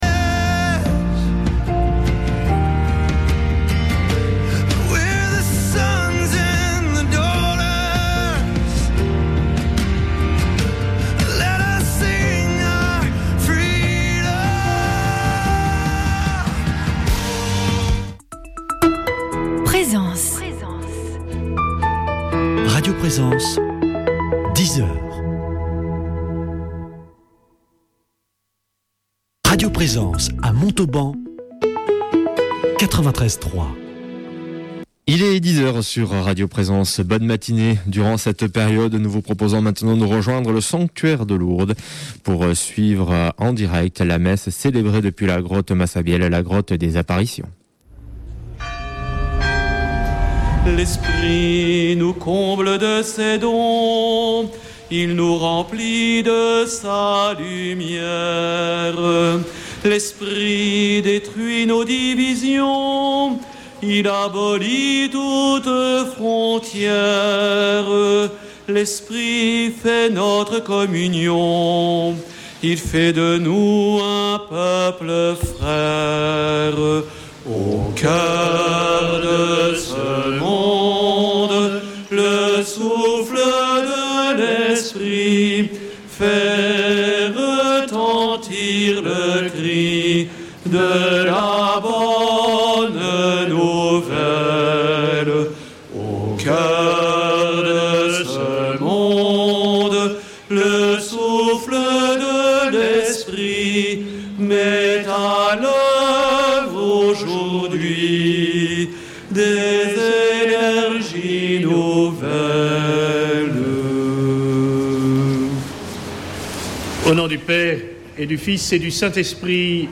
Messe depuis le sanctuaire de Lourdes du 17 mai